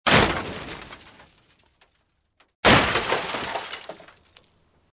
Звуки аварии
Треск и разрушение боковых окон автомобиля